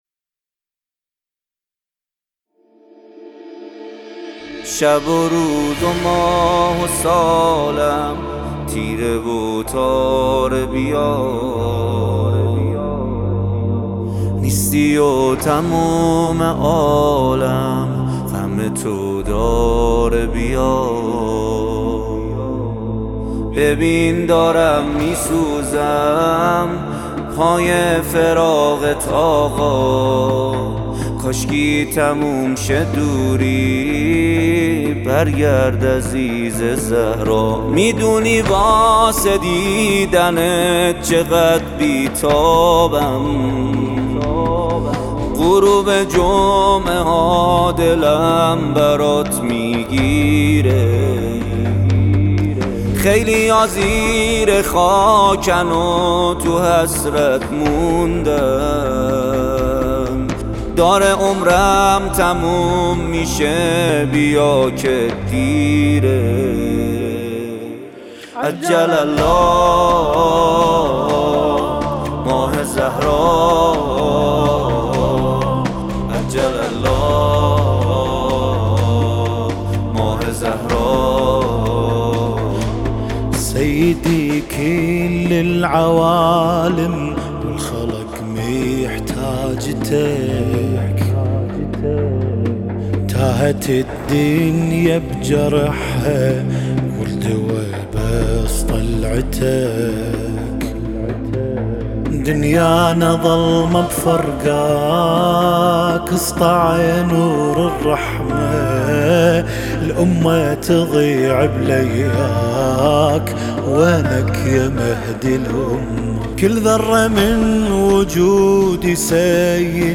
به مناسبت نیمه شعبان منتشر می شود.